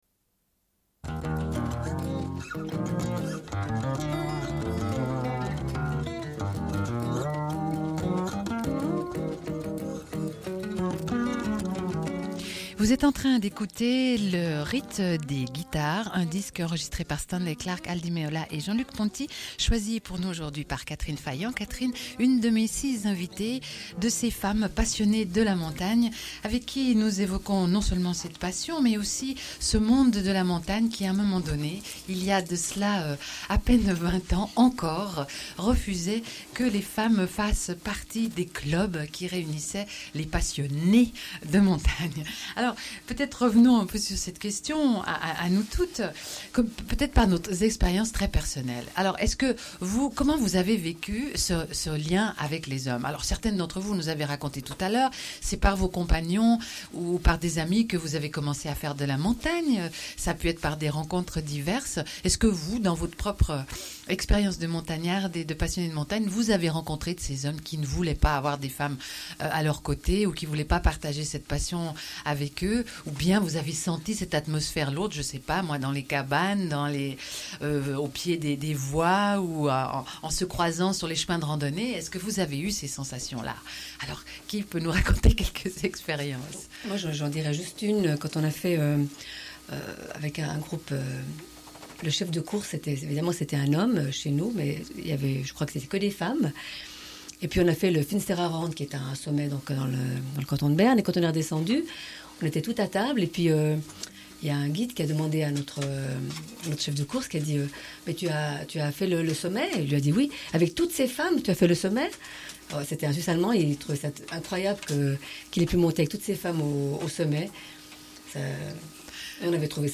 Suite de l'émission : à l'occasion des 20 ans du Groupe alpin genevois (GAG), avec six femmes en direct.